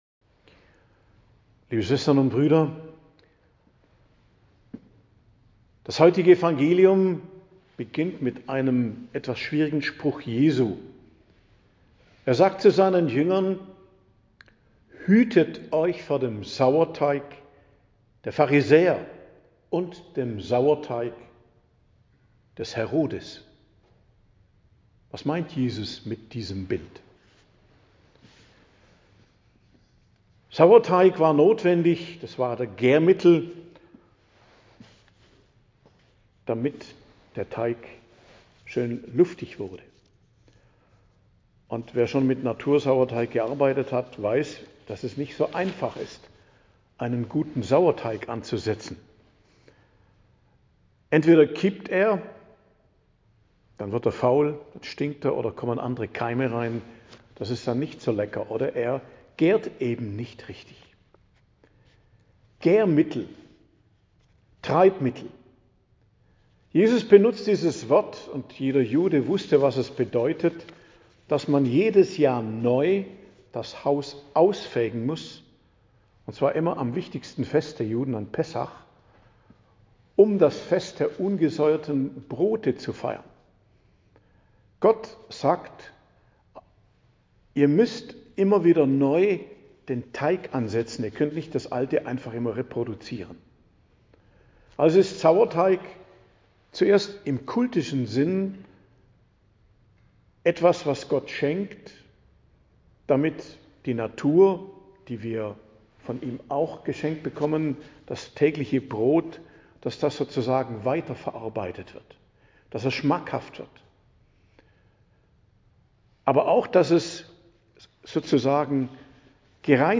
Predigt am Dienstag der 6. Woche i.J. 18.02.2025 ~ Geistliches Zentrum Kloster Heiligkreuztal Podcast